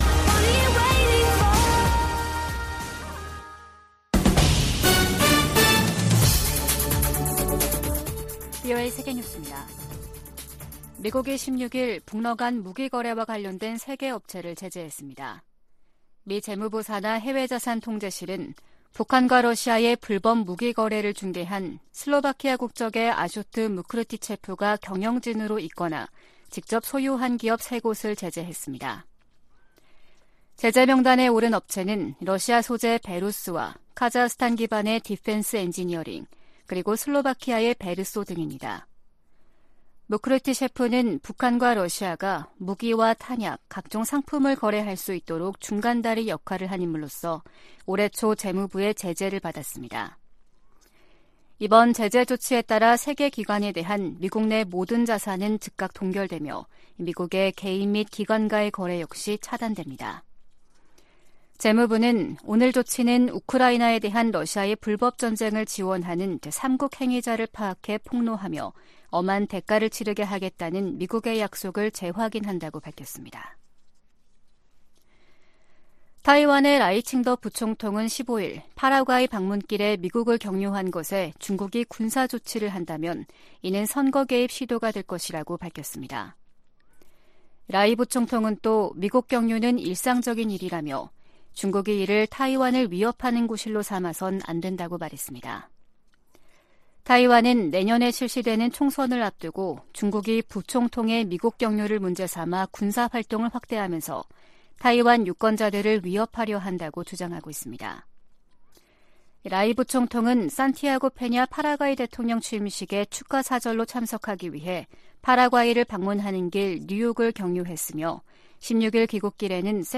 VOA 한국어 아침 뉴스 프로그램 '워싱턴 뉴스 광장' 2023년 8월17일 방송입니다. 토니 블링컨 미 국무장관은 다가오는 미한일 정상회의와 관련해 3국 협력의 중요성을 강조했습니다. 국무부는 이번 회의가 다른 나라의 전략적 안전을 해친다는 중국의 주장을 일축했습니다. 윤석열 한국 대통령은 미한일 정상회의를 앞두고 확장억제와 관련해 미한일 사이 별도의 협의도 열려 있다고 밝혔습니다.